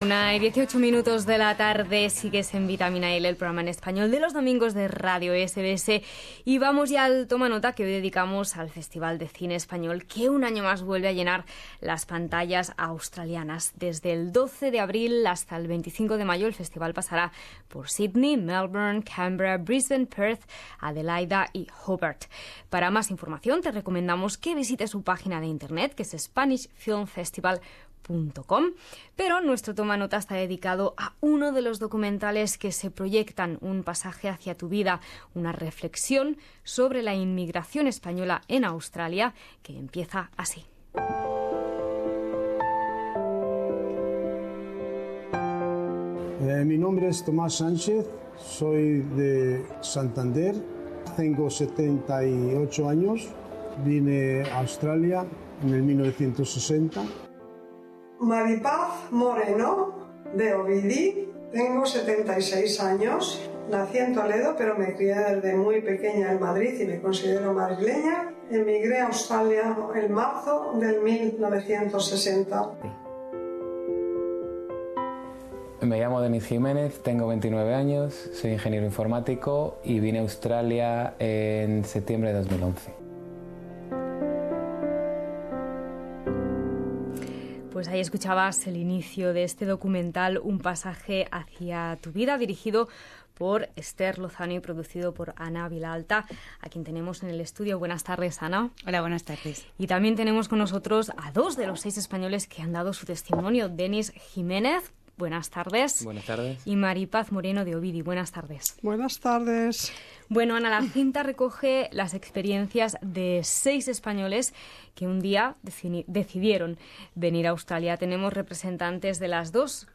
en los estudios de Radio SBS